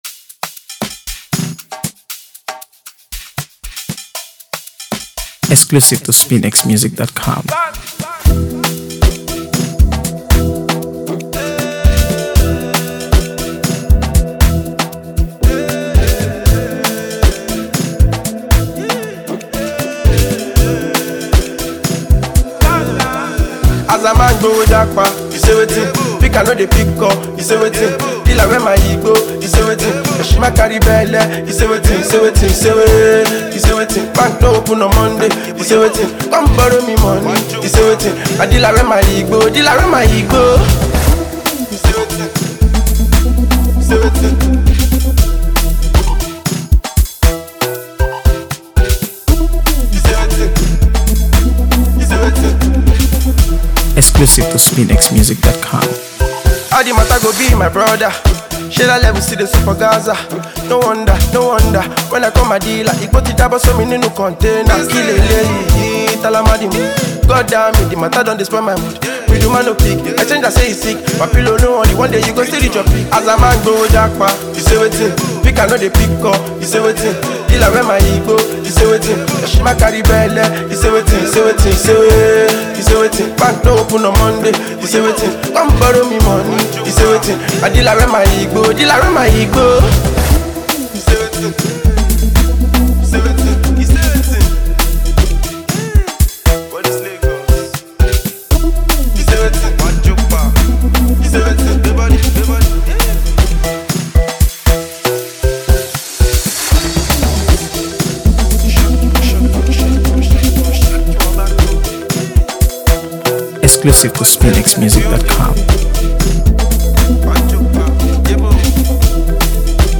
AfroBeats | AfroBeats songs